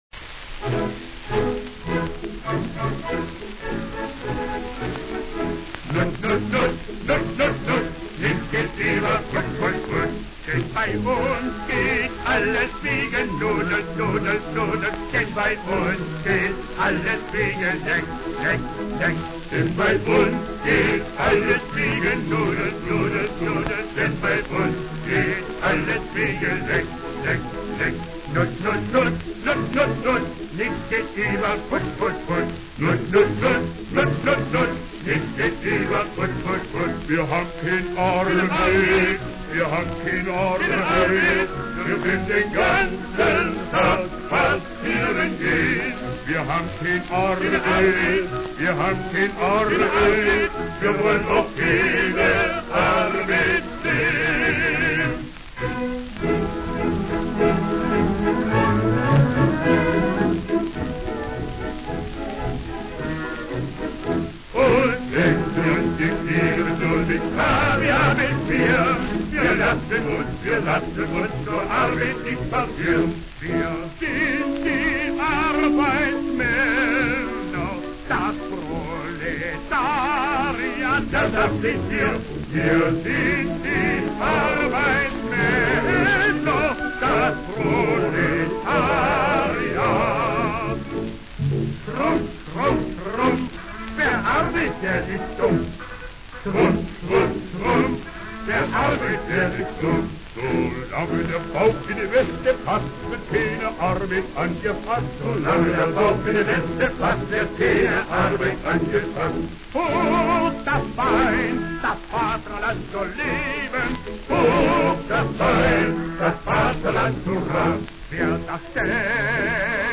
mit Gesang